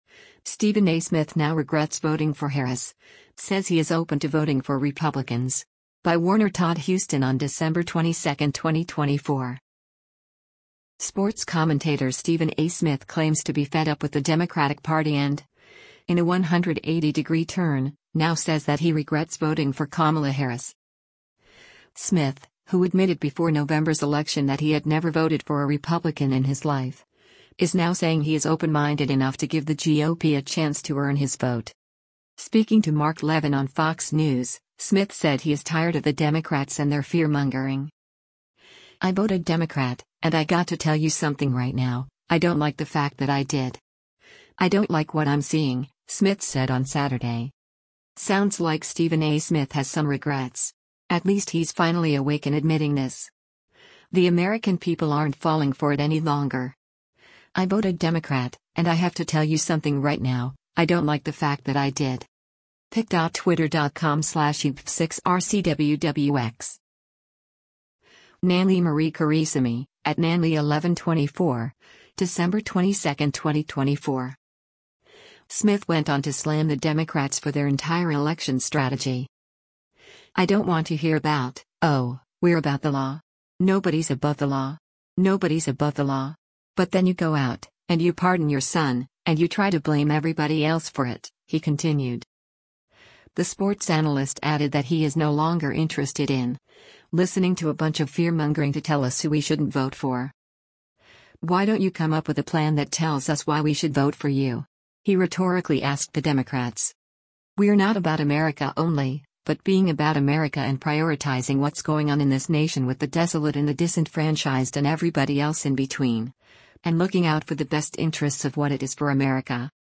Speaking to Mark Levin on Fox News, Smith said he is tired of the Democrats and their “fear-mongering.”